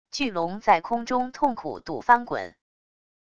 巨龙在空中痛苦赌翻滚wav音频